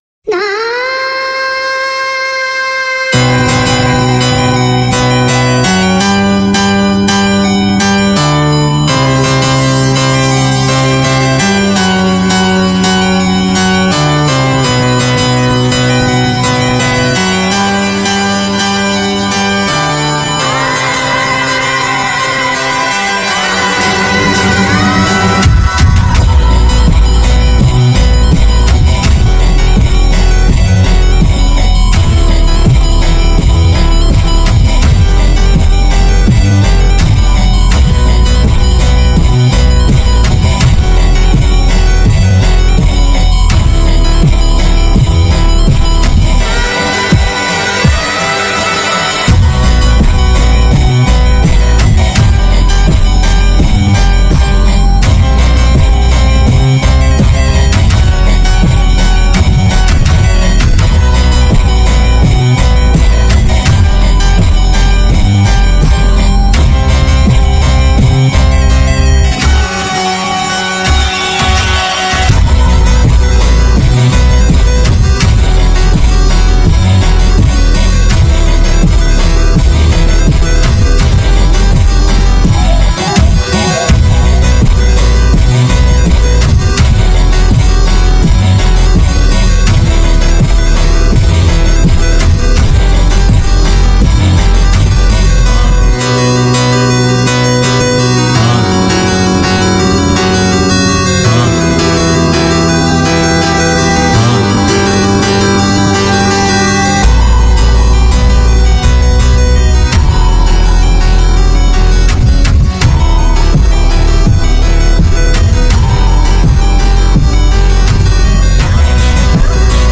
A320 full startup roblox sound effects free download